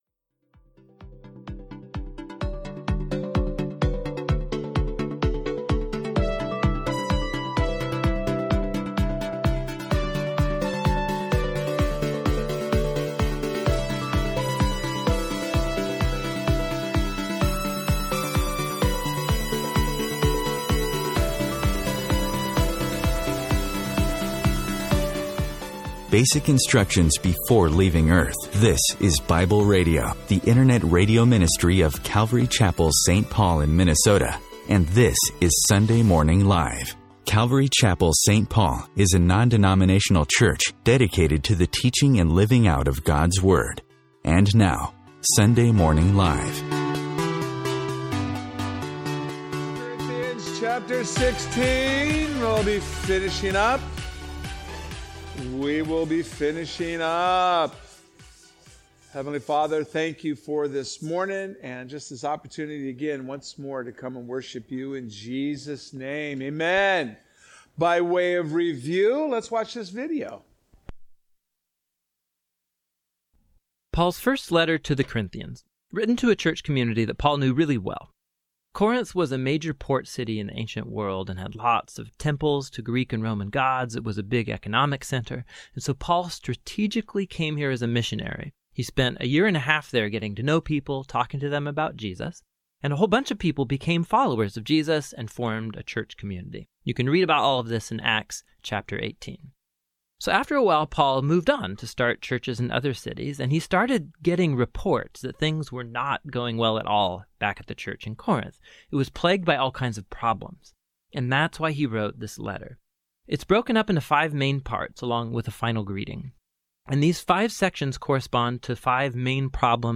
A message from the series "3000 Series."